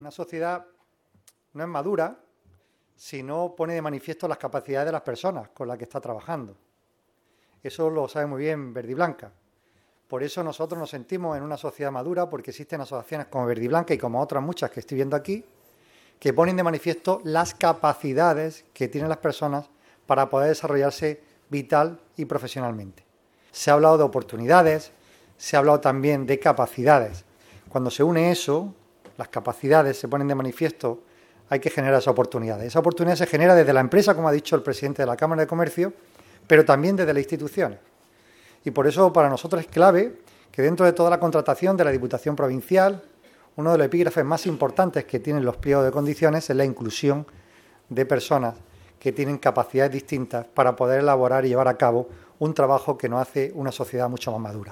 Uno de estos galardones ha recaído en la Diputación de Almería por la labor de inclusión laboral que lleva a cabo en la provincia. El premio lo ha recogido el presidente provincial, Javier A. García.
En esta primera edición han sido entregados a Alcampo Almería, Estrella Indal, Centro de Día Vivaldi, Diputación de Almería, STV & Albaida, Universidad de Almería e IKEA Almería, en un acto desarrollado en la sala de actos de la Cámara de Comercio almeriense y que ha contado con la presencia de representantes de las principales instituciones públicas y privadas, entidades del tercer sector y empresariales.
08-05_verdiblanca_presidente_javier.mp3